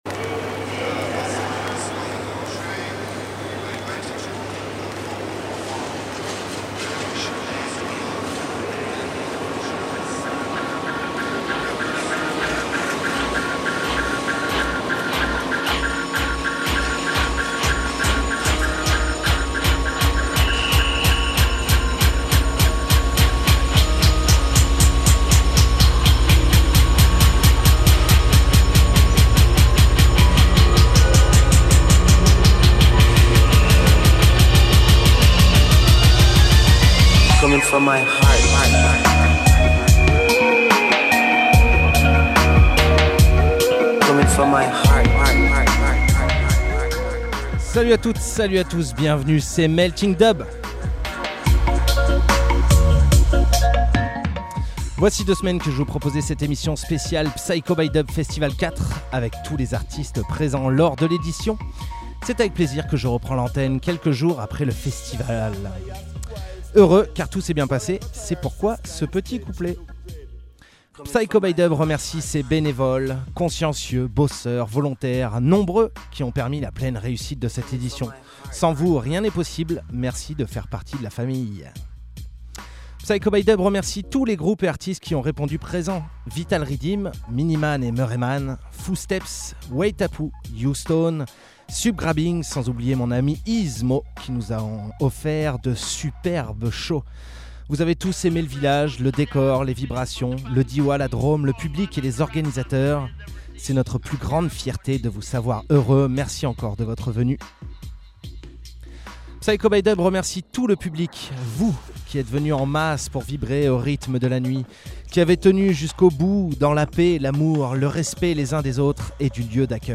bass music , dub , musique , musique electronique , reggae